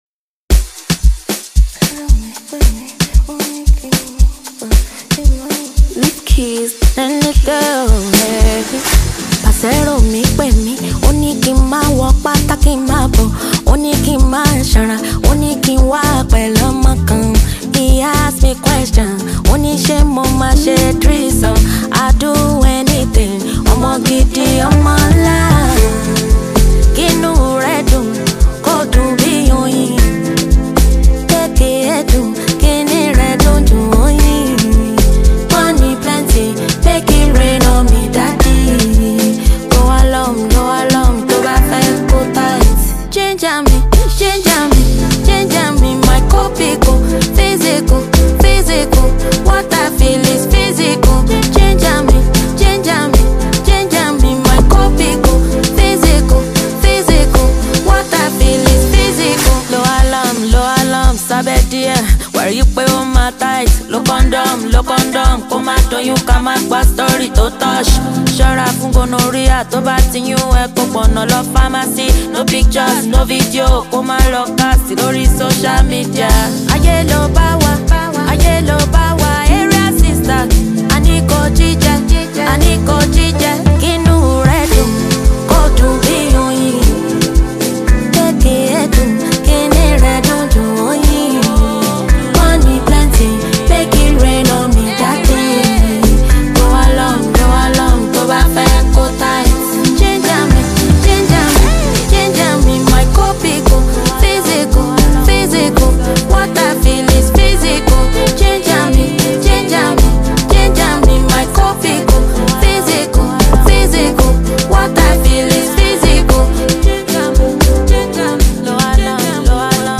a Nigerian Talented Female Singer